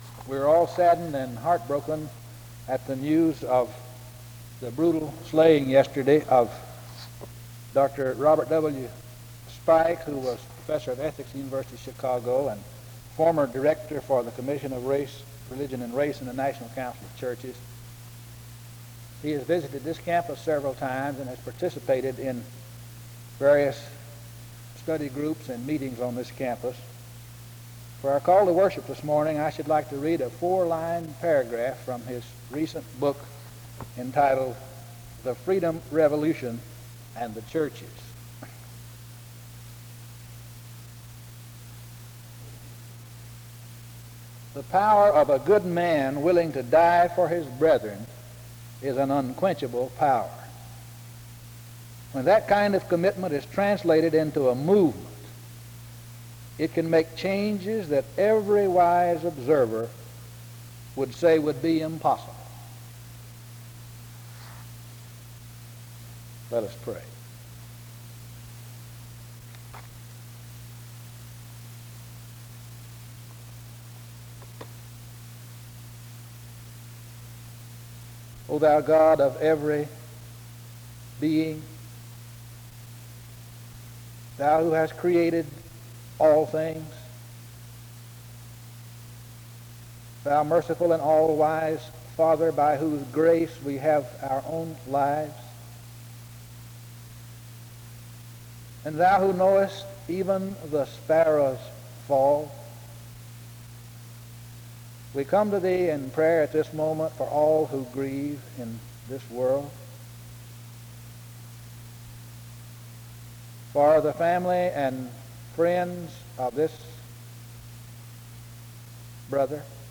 The service begins with an announcement and a reading from 0:00-1:06. A prayer is offered from 1:08-5:31. An introduction to the speaker is given from 5:33-6:24. Music plays from 6:27-7:04.